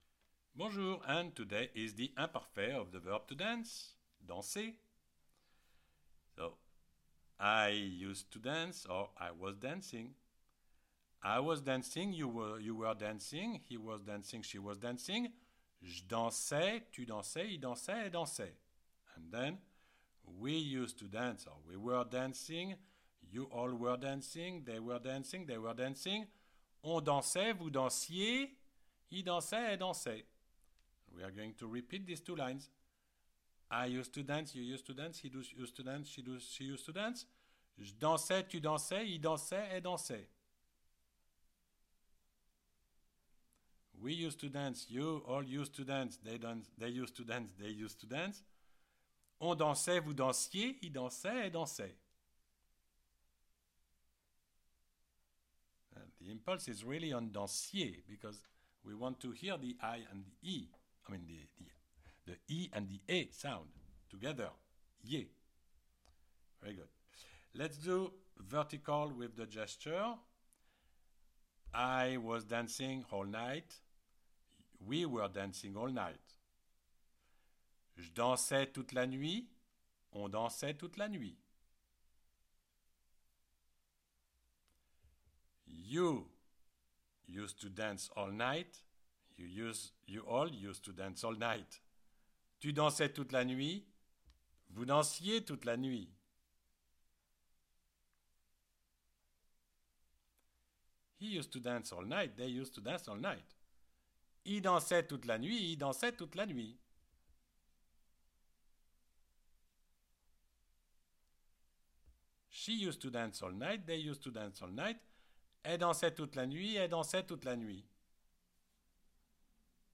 CLICK ON THE PLAY BUTTON BELOW TO PRACTICE ‘DANSER’, ‘TO DANCE’, IN THE IMPARFAIT TENSE
You read and you repeat with the audio.